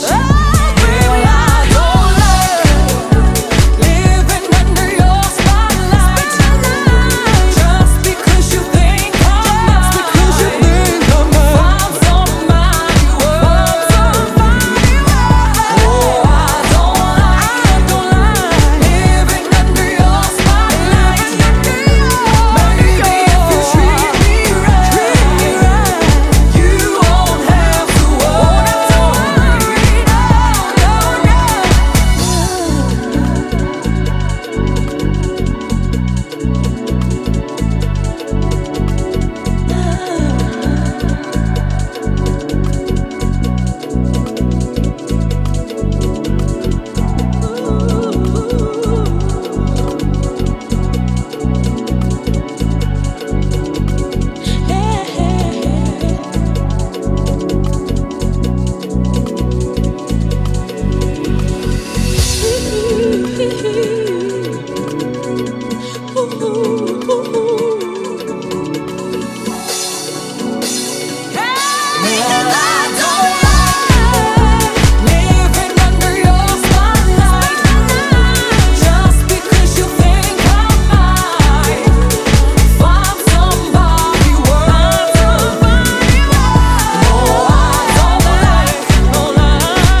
ジャンル(スタイル) DEEP HOUSE / HOUSE